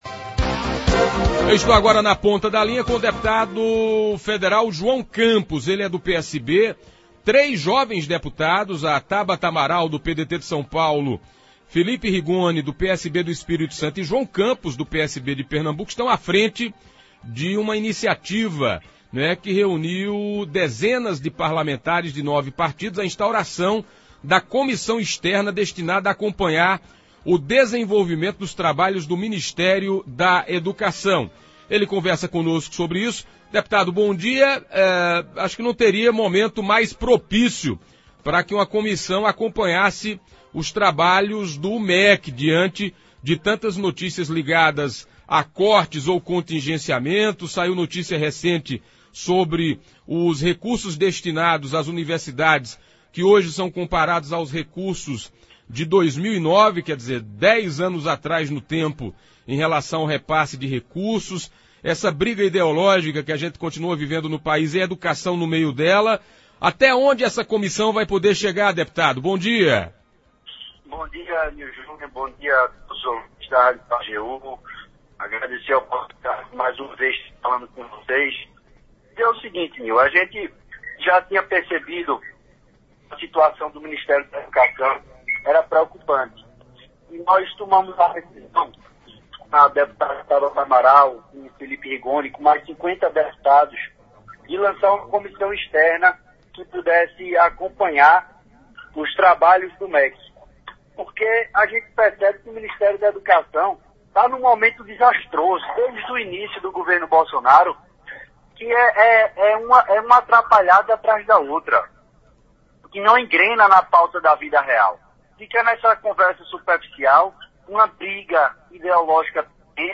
O deputado federal João Campos (PSB-PE), participou na manhã desta sexta-feira (17), por telefone, do programa Manhã Total da Rádio Pajeú FM 104,9.